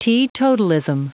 Prononciation du mot teetotalism en anglais (fichier audio)
Prononciation du mot : teetotalism